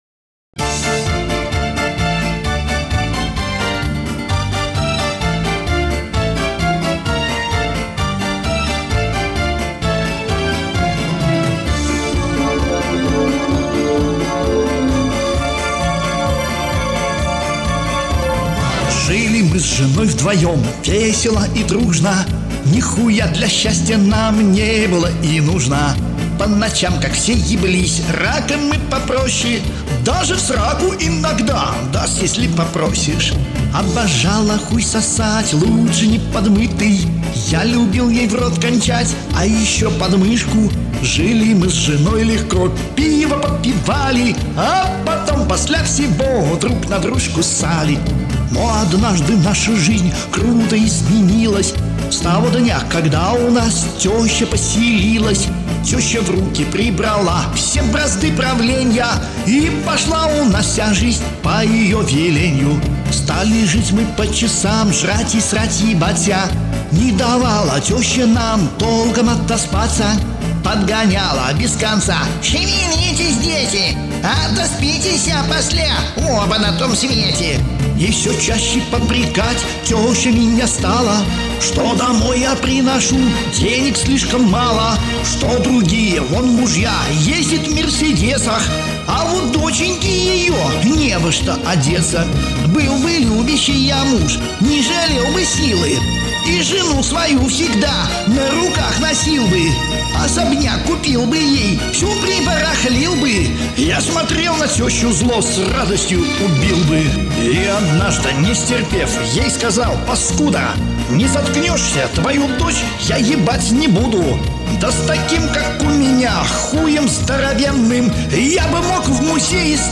Maternye_chastushki___ZHILI_MY_S_ZHENOJ_VDVOEM_iPleer_fm.mp3